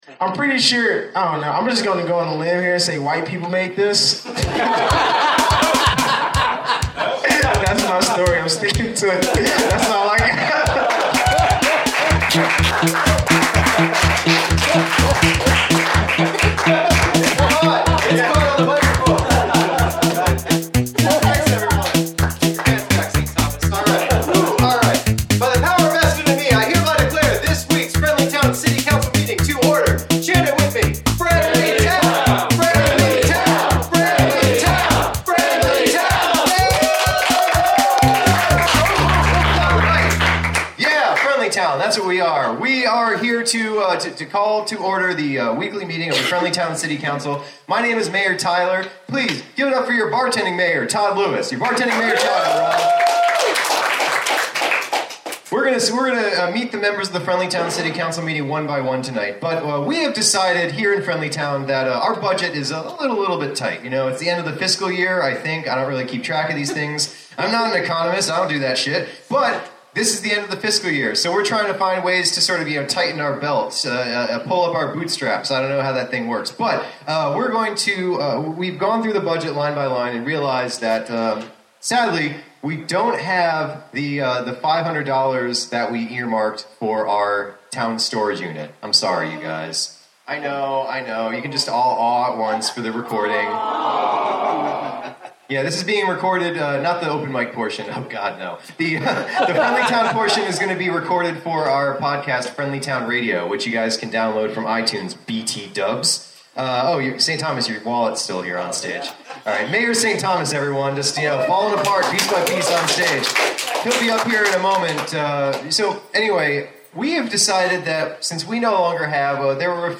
Special appearances by comics from New York and Mobile!
Recorded Live at the Pilot Light October 16, 2017, Knoxville TN